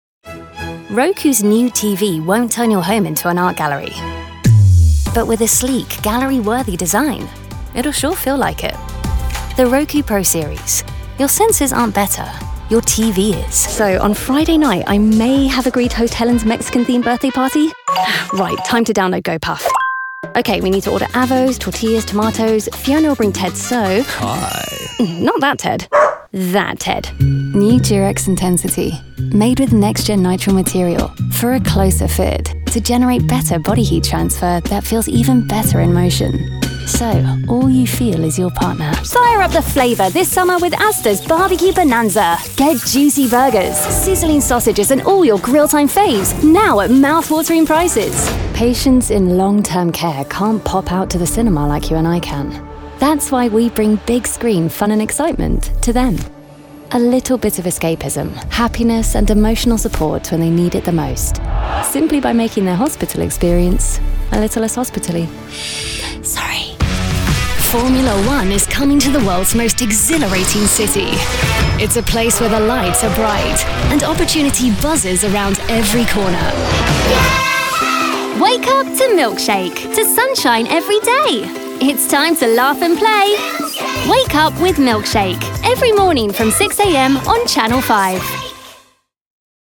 Young Adult, Adult
• Studio | Fully acoustically treated broadcast quality vocal booth
• Microphone | Rode NT1-A
british rp | natural
international english
mid-atlantic
standard british | natural
standard us | natural
COMMERCIAL 💸